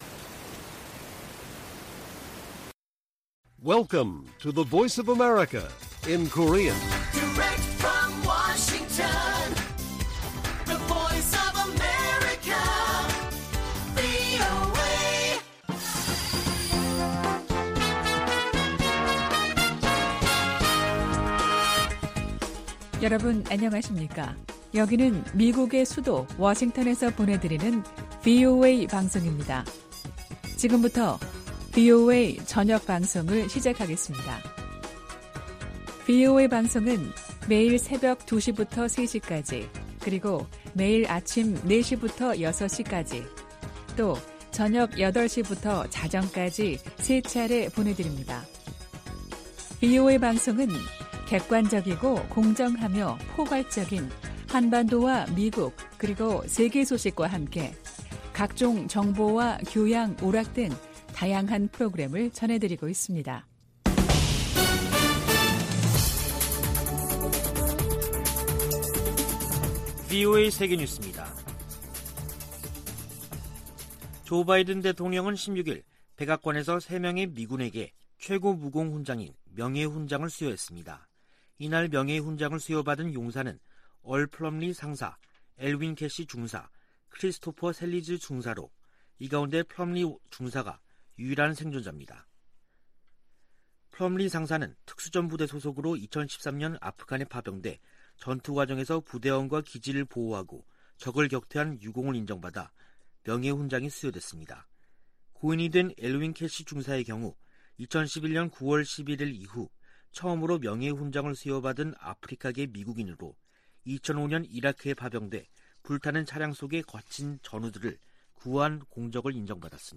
VOA 한국어 간판 뉴스 프로그램 '뉴스 투데이', 2021년 12월 17일 1부 방송입니다. 미 국무부는 북한이 반복적으로 국제 테러 행위를 지원하고 있다고 보고서에서 지적했습니다. 미국은 북한과 대화와 외교를 통한 한반도의 항구적인 평화를 위해 노력하고 있다고 국무부 부차관보가 밝혔습니다. 미 국무부가 미한 동맹의 중요성을 거듭 확인하면서 중국과 관여하는 문제에도 협력을 강조했습니다.